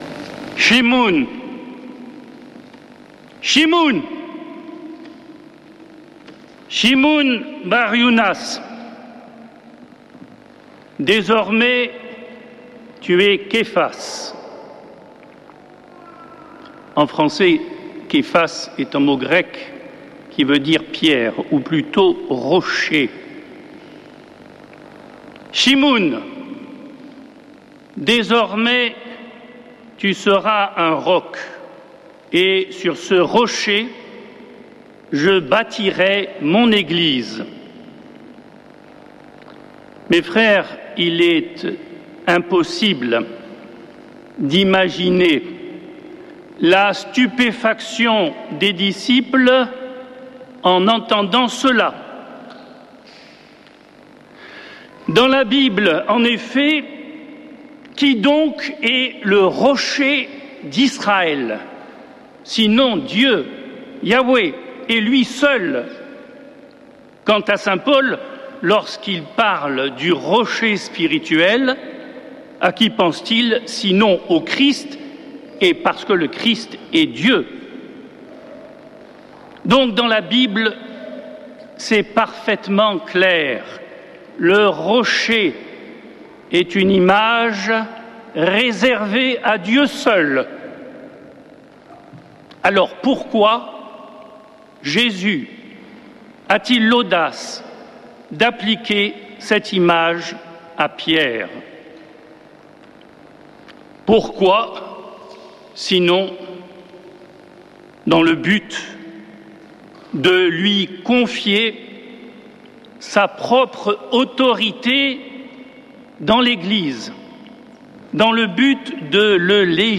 Homélie du 29 juin
Messe depuis le couvent des Dominicains de Toulouse